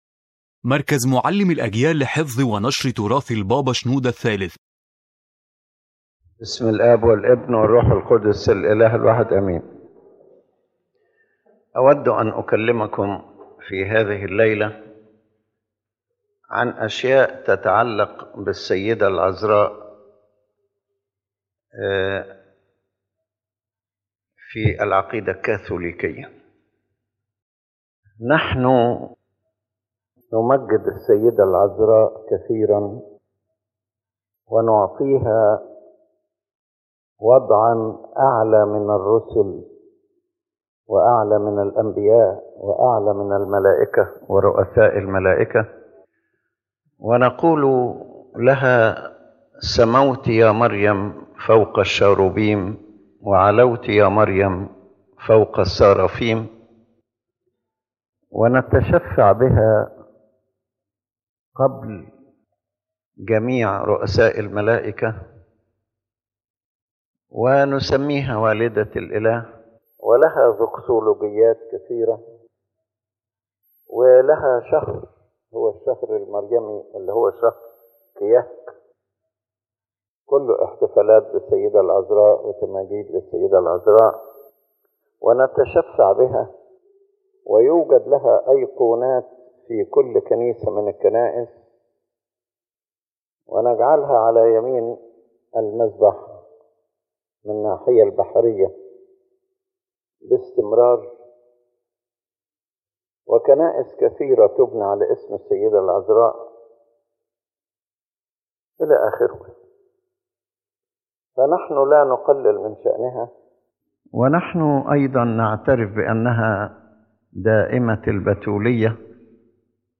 This lecture presents and critically examines certain Catholic teachings concerning the Virgin Mary, while clarifying the position of the Coptic Orthodox faith, which glorifies the Virgin without exaggeration that affects the essence of redemption and the doctrine of salvation through Christ alone.